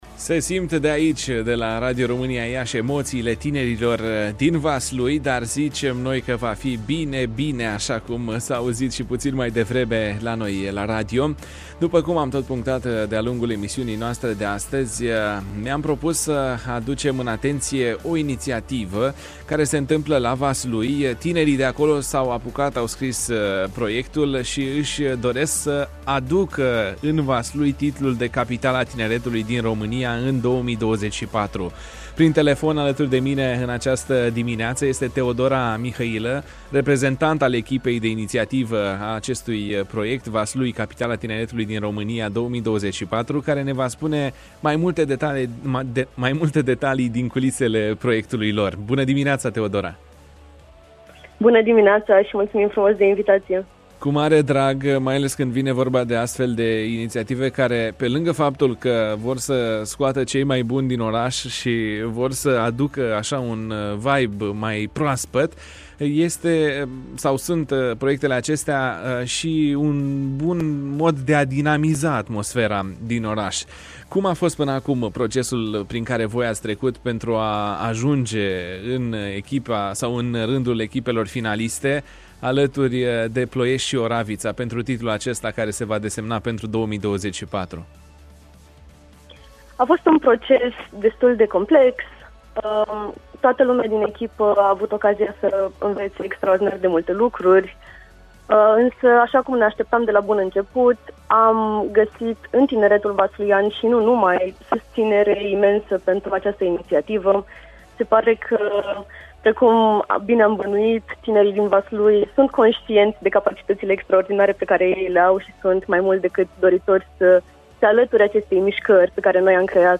În emisiunea „Bună dimineața”